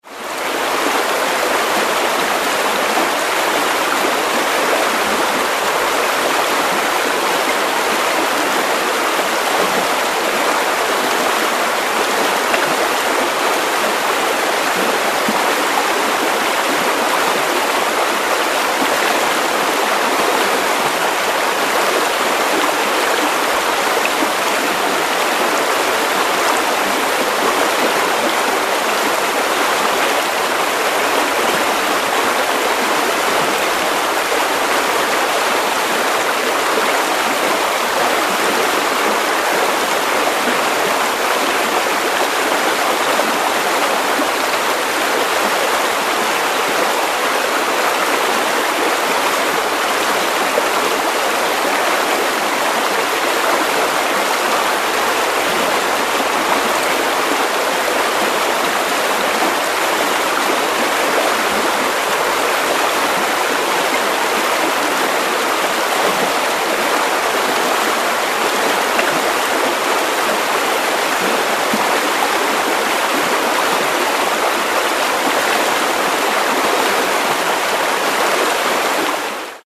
Звуки реки